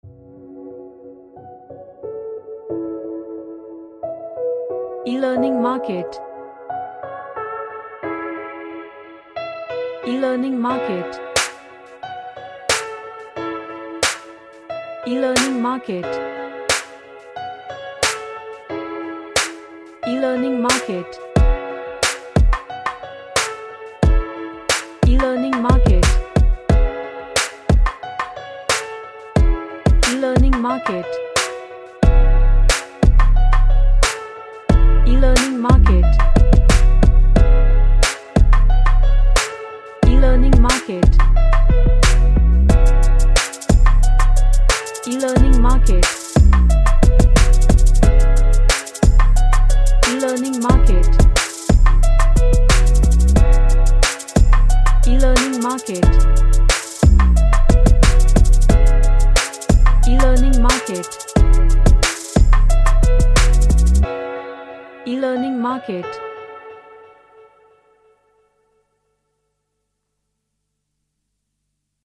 A hip hop bounce track
Strange / Bizarre